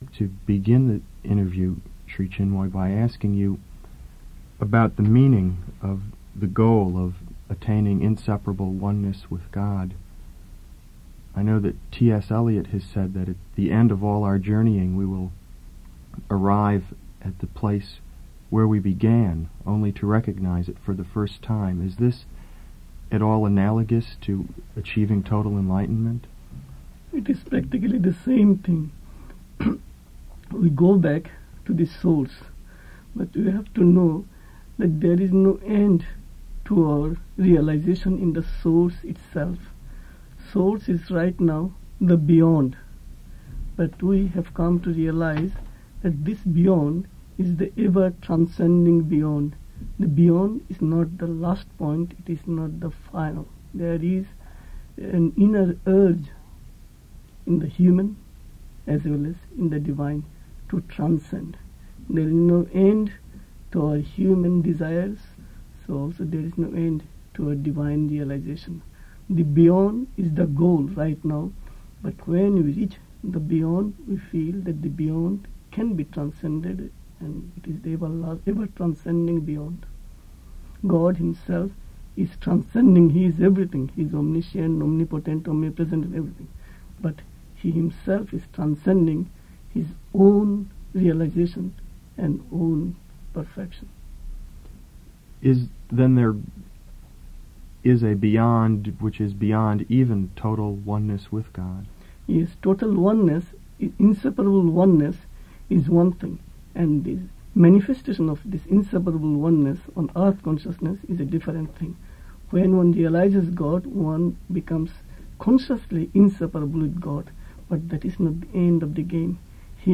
Interviews with Sri Chinmoy